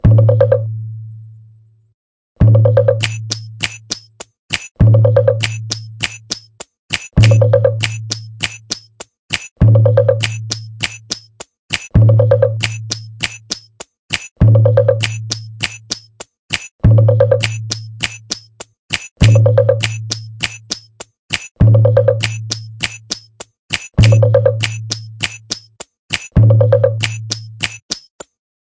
混响鼓
描述：声音，鼓，舞